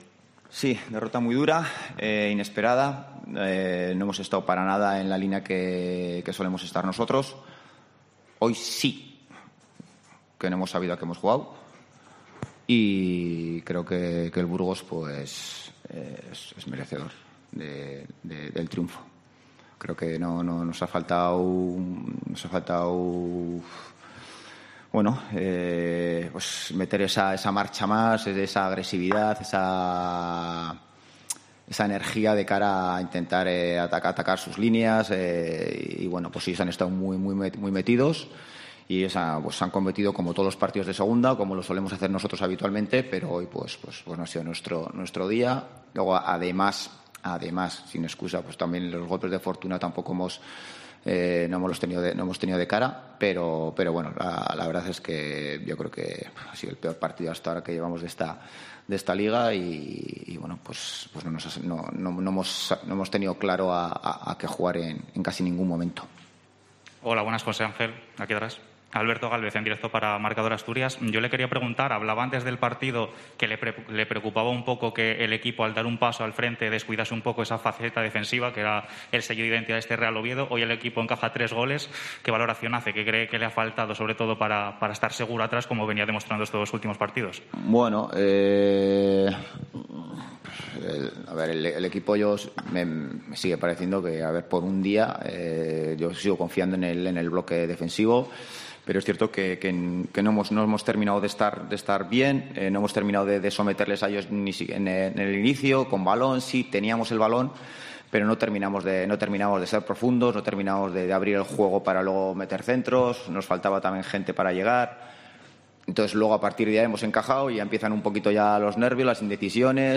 Rueda de prensa Ziganda (Oviedo-Burgos)